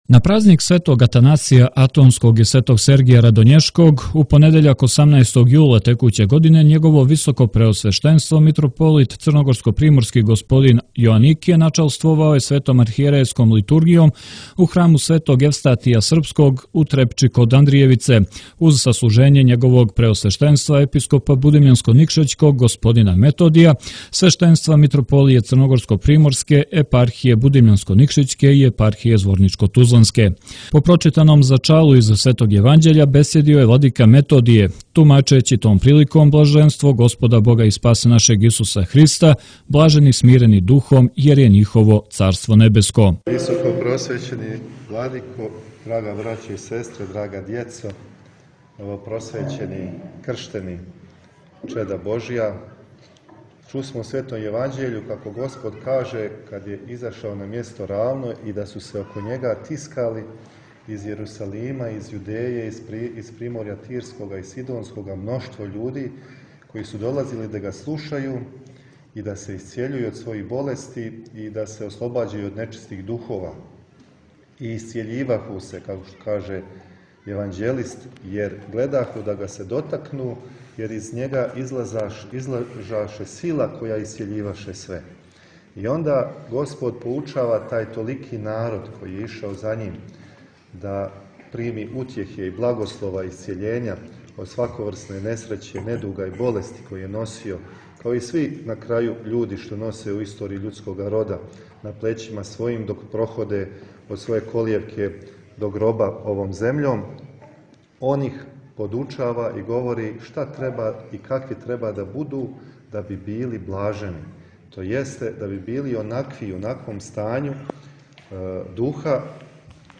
Архијерејска Литургија у Трепчи: Све на овоме свијету је пролазно, осим онога што се чини у славу Божију • Радио ~ Светигора ~
Његово високопреосвештенство Митрополит црногорско-приморски г. Јоаникије и Његово преосвештенство Епископ будимљанско-никшићки г. Методије данас, на празник Св. Сергија Радоњешког, 18. јула, служили су Свету архијерејску литургију у Цркви Светог Јевстатија Српског у Трепчи, уз саслужење свештенства и свештеномонаштва Митрополије црногорско-приморске и епархија Будимљанско-никшићке и Зворничко тузланске и молитвено учешће бројних вјерника.